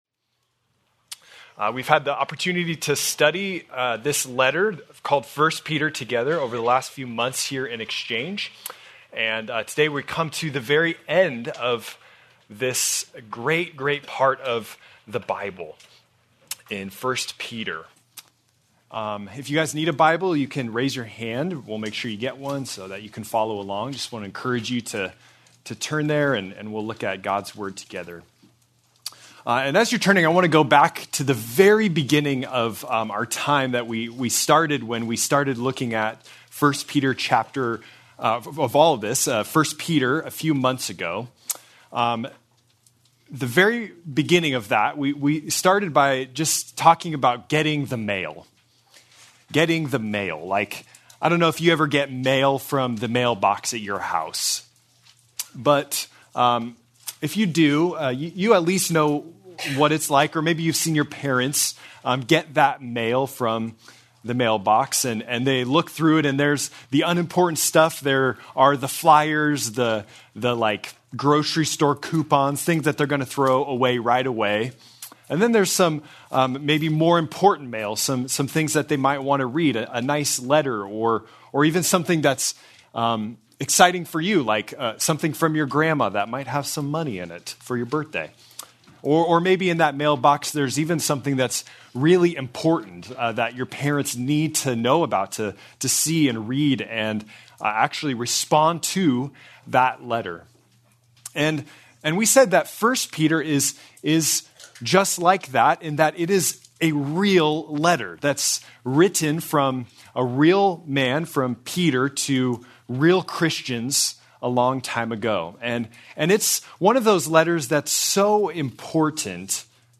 February 22, 2026 - Sermon | Xchange | Grace Community Church